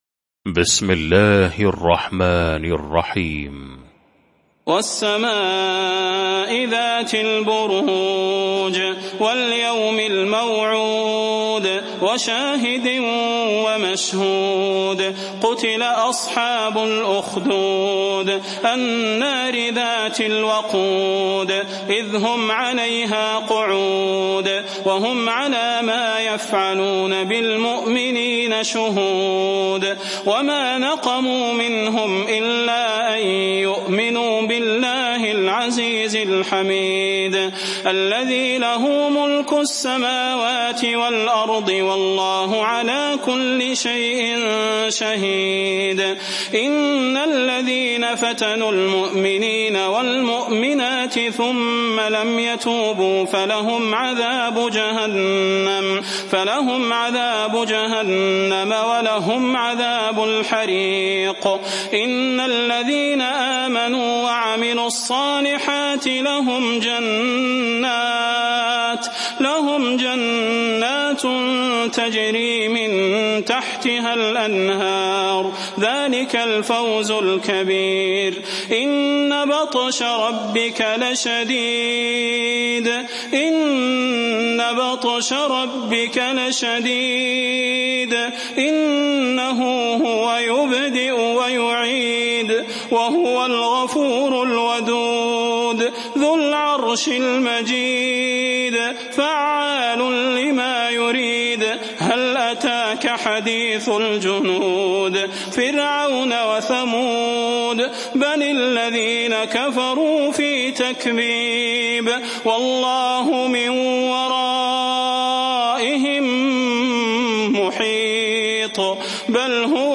المكان: المسجد النبوي الشيخ: فضيلة الشيخ د. صلاح بن محمد البدير فضيلة الشيخ د. صلاح بن محمد البدير البروج